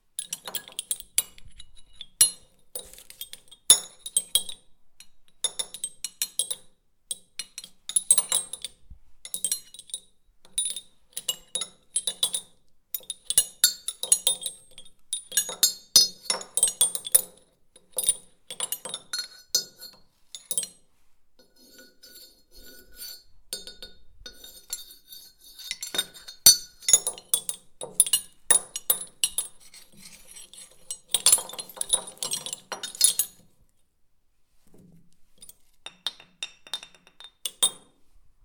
Metal_Rattle_Small_Melodic
cling ding melodic metal rattle small sound effect free sound royalty free Sound Effects